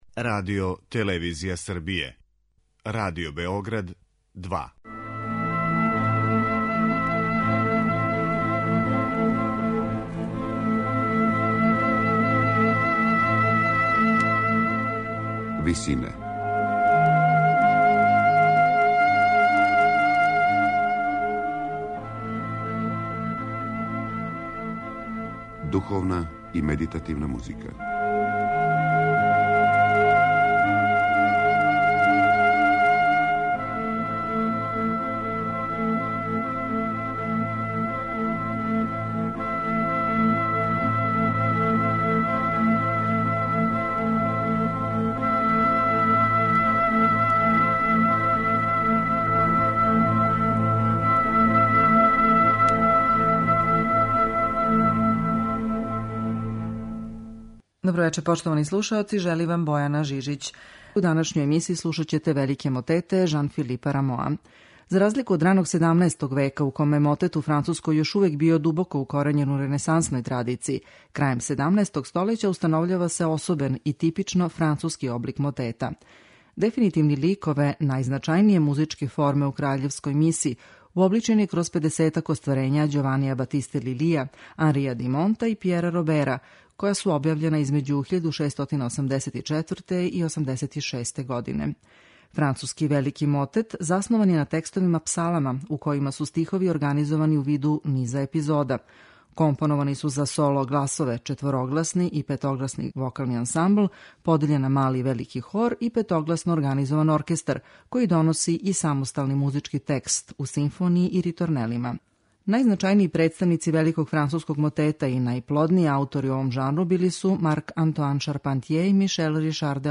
Међу њима су композиције које припадају особеном и типичном француском облику - велики мотети.
у ВИСИНАМА представљамо медитативне и духовне композиције аутора свих конфесија и епоха.